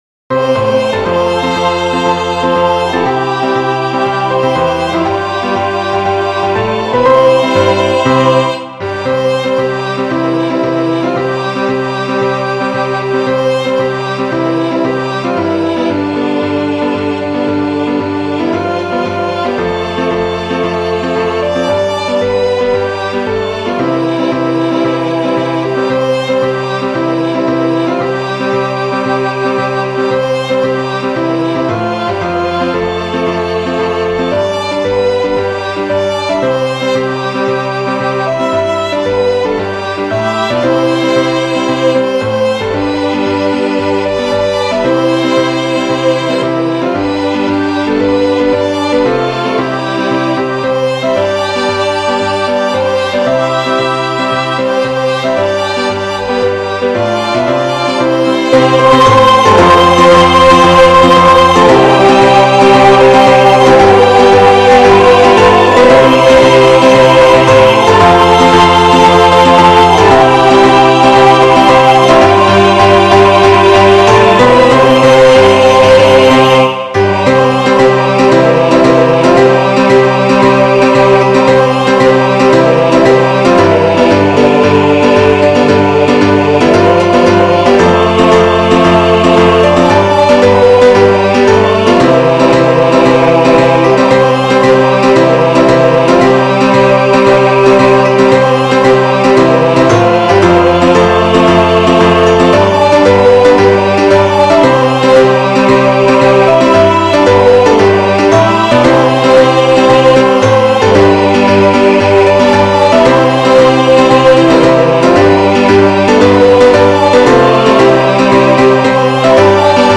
HINO DO REAL SPORT CLUBE